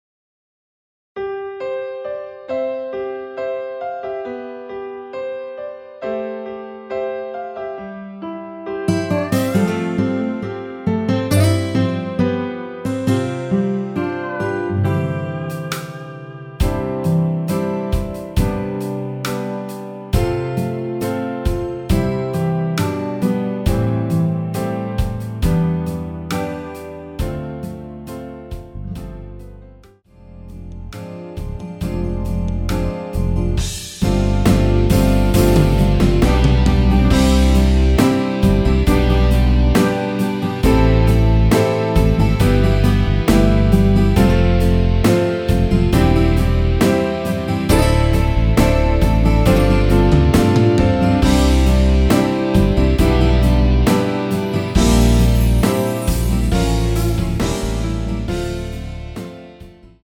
원키에서(-1)내린 MR입니다.
◈ 곡명 옆 (-1)은 반음 내림, (+1)은 반음 올림 입니다.
앞부분30초, 뒷부분30초씩 편집해서 올려 드리고 있습니다.